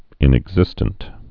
(ĭnĭg-zĭstənt)